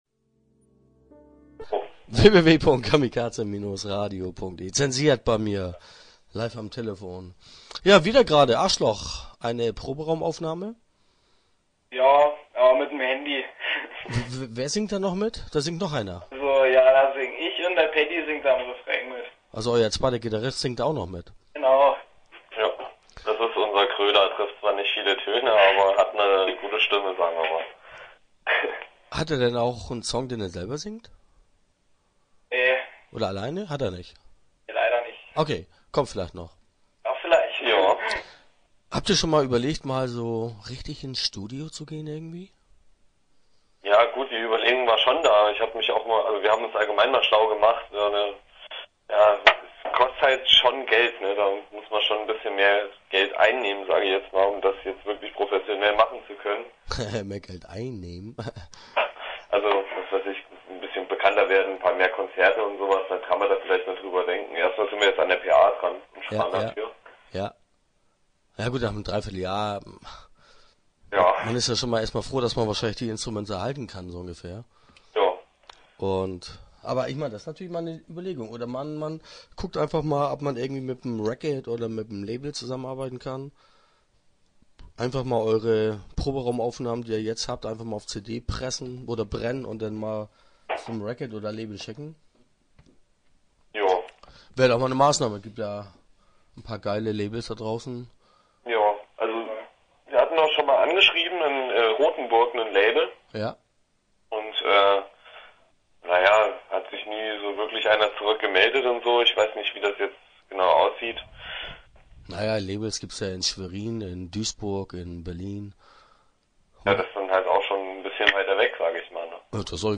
Start » Interviews » Zensiert